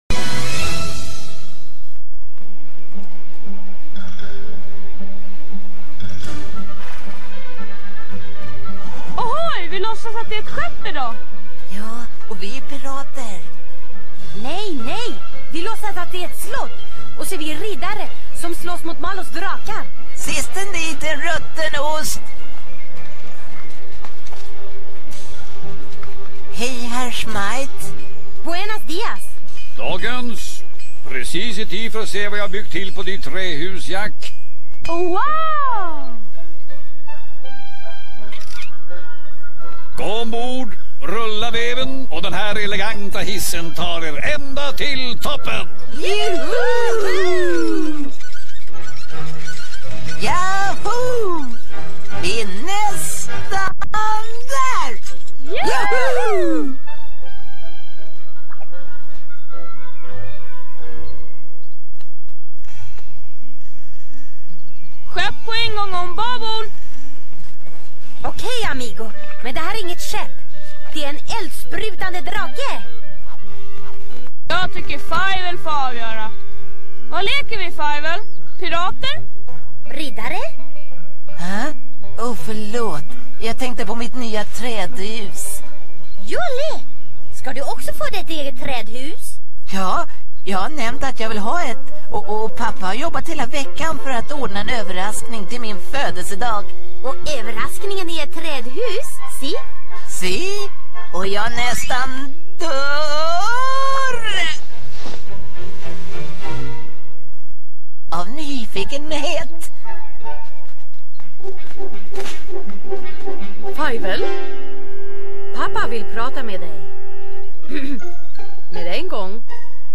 Tecknat Barn Svenska:Resan Till Amerika Vilda Västern (1992 TV Serie) VHSRIPPEN (Svenska) Fångarensgåvan (3D)